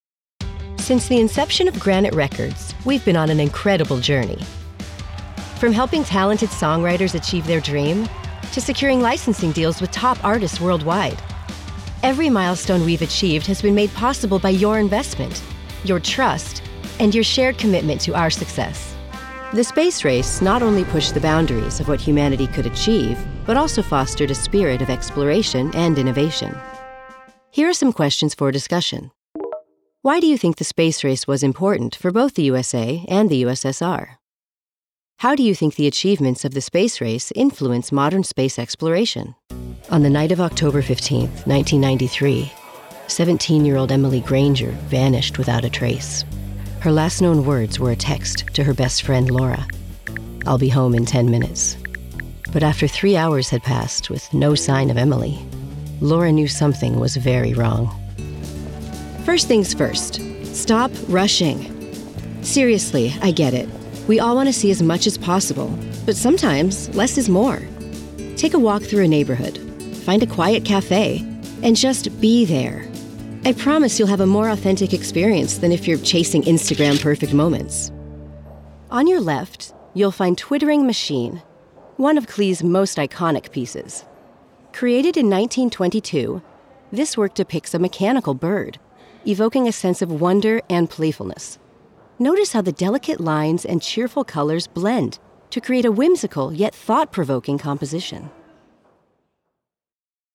Narration demo reel
Standard American English
Middle Aged